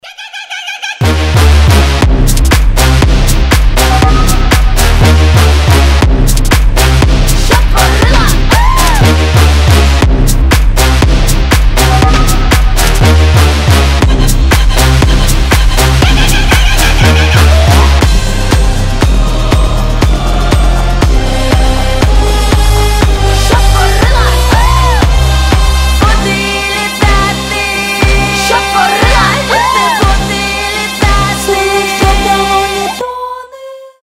• Качество: 320, Stereo
громкие
мощные басы
необычные
эпичные
Neoclassical
Самое необычное звучание в современной электронной музыке